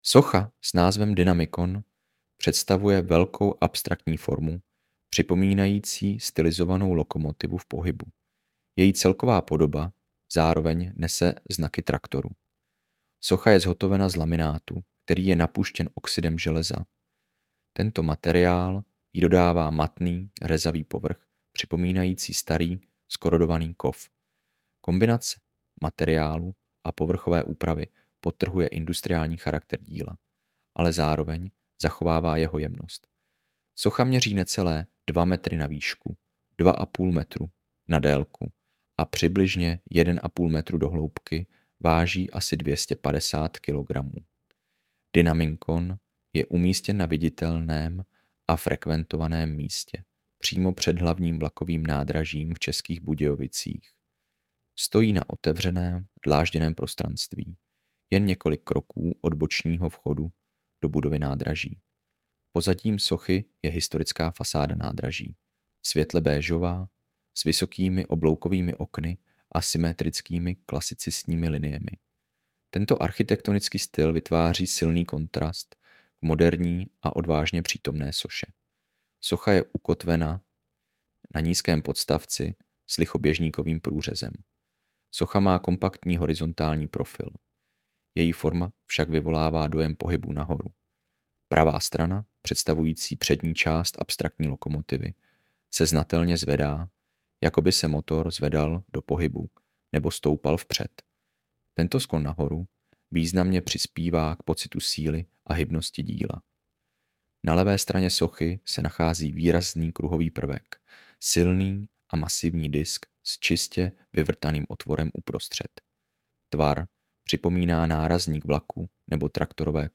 AUDIOPOPIS